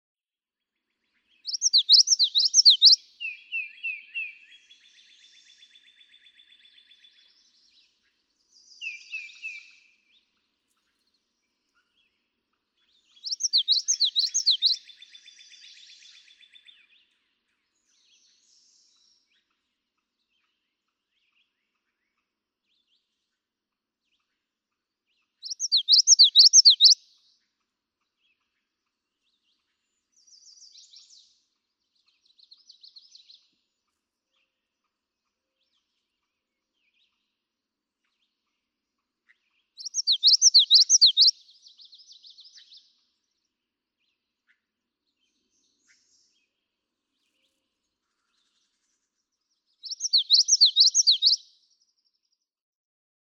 May 30, 2015. East Leverett Meadow, Leverett, Massachusetts.
♫218, ♫219—longer recordings from those two neighbors
218_Common_Yellowthroat.mp3